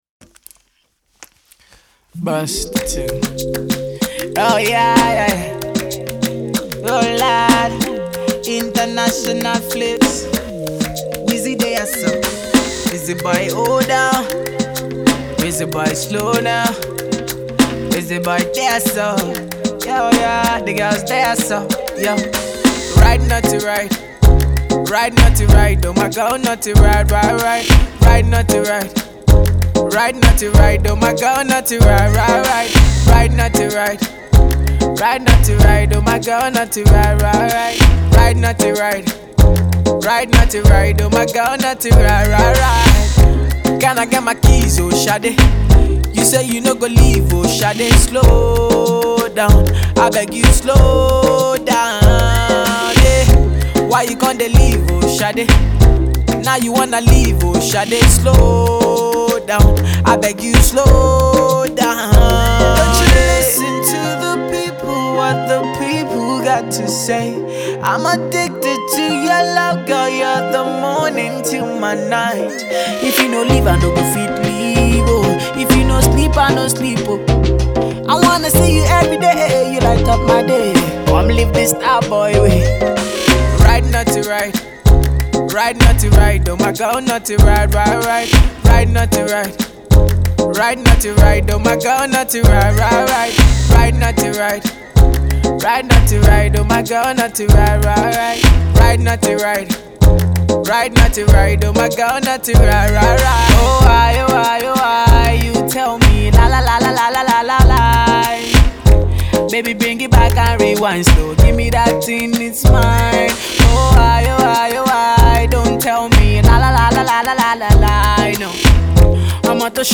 We love the dancehall feel.